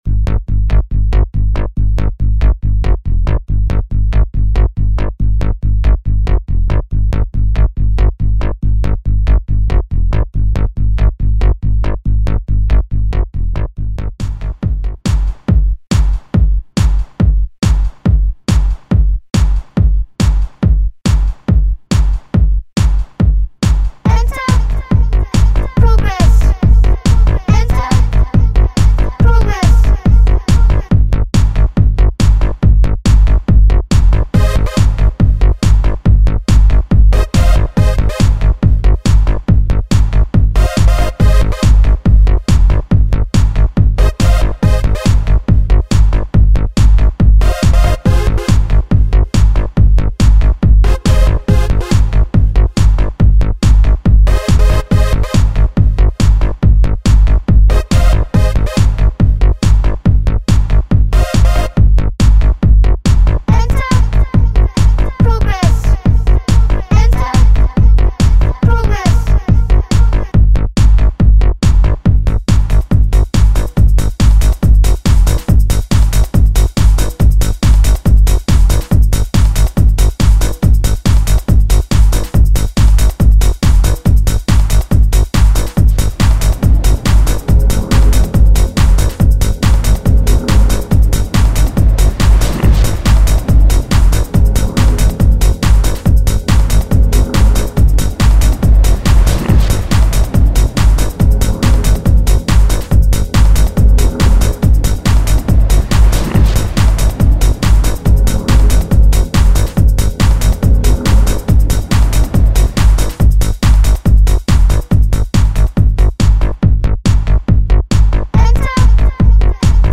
Home > Music > Ambient > Electronic > Running > Chasing